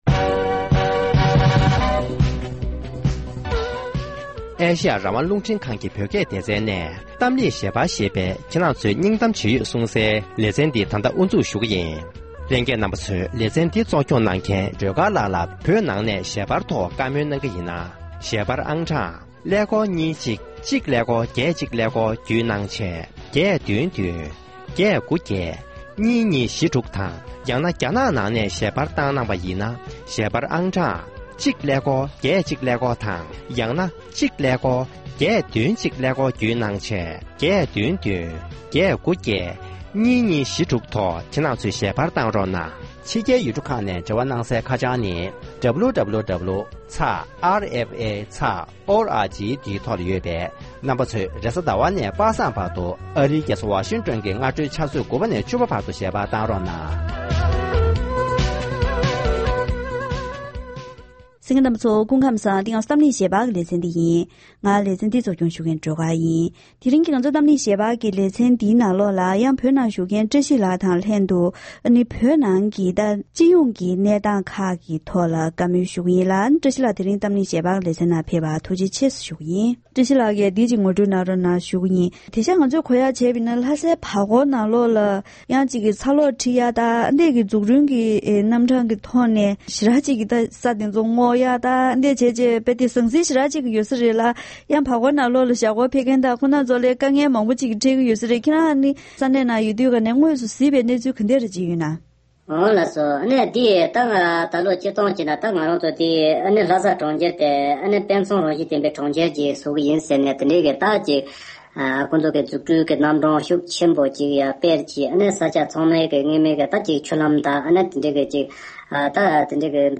༄༅༎དེ་རིང་གི་གཏམ་གླེང་ཞལ་པར་ལེ་ཚན་ནང་བོད་ནང་བཞུགས་མཁན་བོད་མི་ཞིག་དང་ལྷན་དུ་ལྷ་སའི་བར་སྐོར་ནང་གི་འཛུགས་སྐྲུན་ལས་གཞི་དང་།ལྷ་སའི་ནང་གི་དམ་བསྒྲགས་གནང་སྟངས།དེ་བཞིན་བོད་ནང་གི་སྤྱི་ཡོངས་གནས་སྟངས་སྐོར་ལ་བཀའ་མོལ་ཞུས་པ་ཞིག་གསན་རོགས་གནང་།།